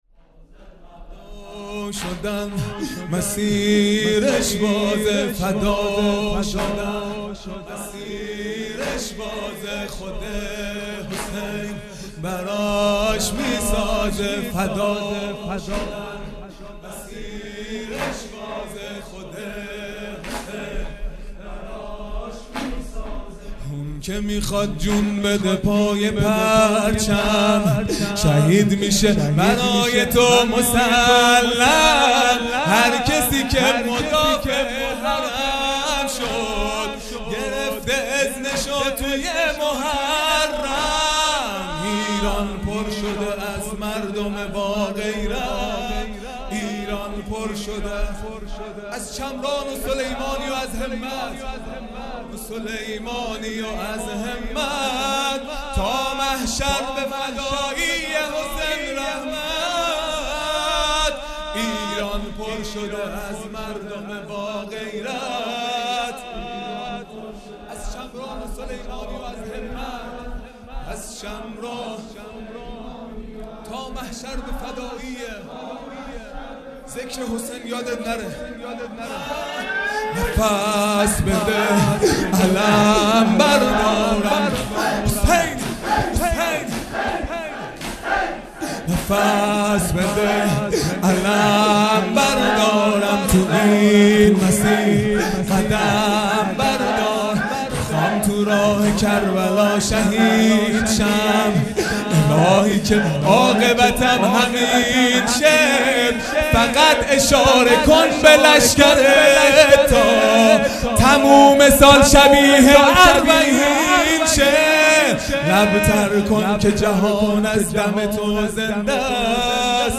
خیمه گاه - هیئت بچه های فاطمه (س) - تک | فداشدن مسیرش بازه
دهه اول محرم الحرام ۱۴۴٢ | شب تاسوعا